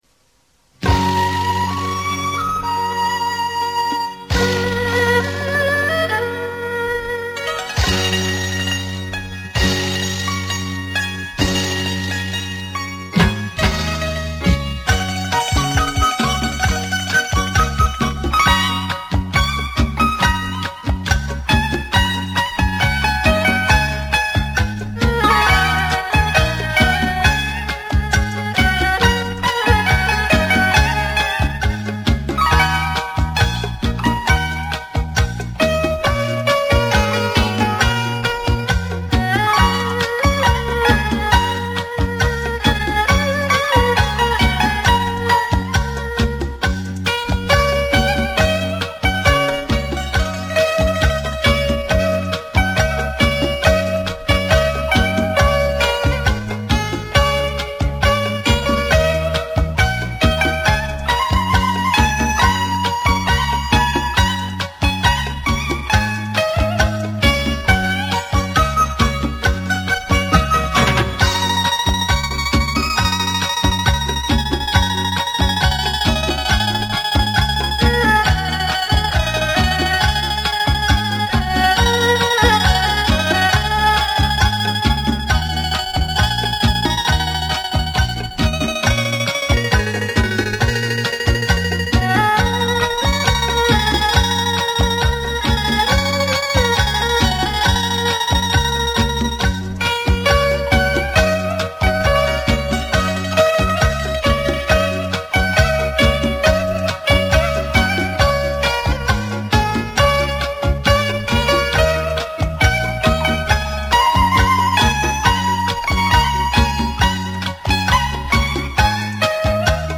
[18/6/2017]我的收藏~一首好听的古筝演奏